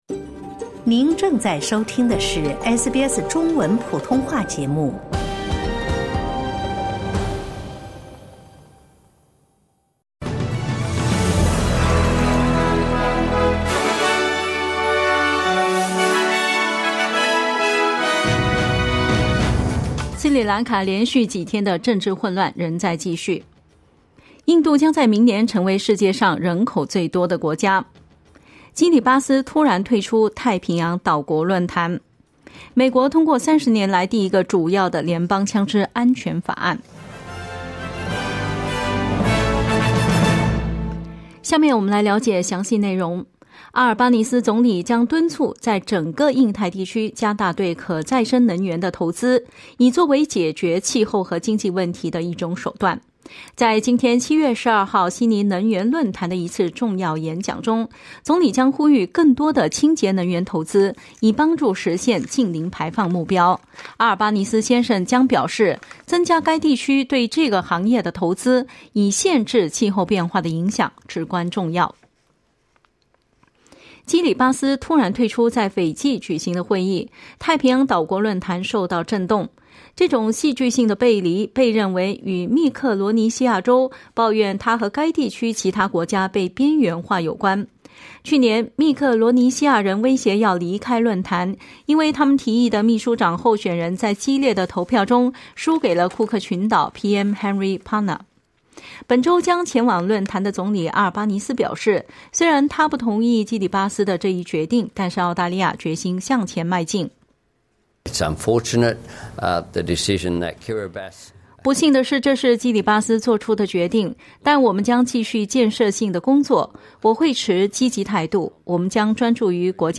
SBS早新闻（7月12日）
请点击收听SBS普通话为您带来的最新新闻内容。